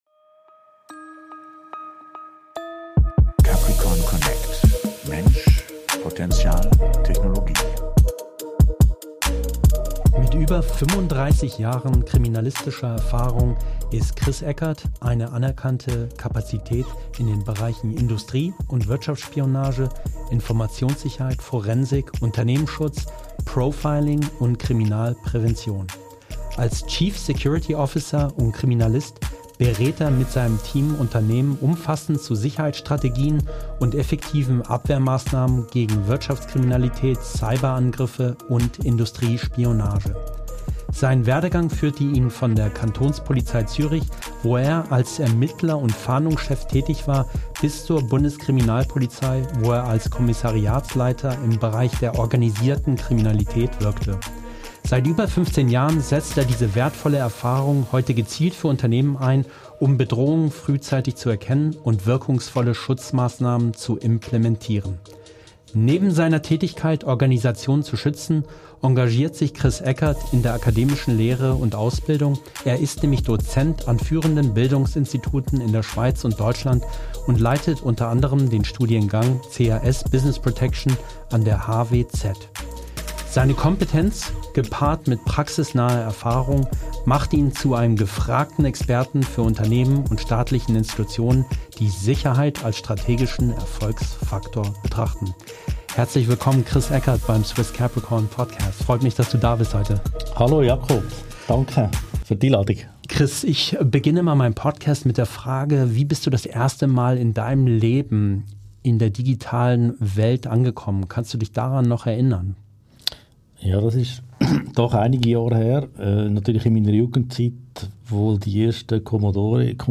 #46 - Interview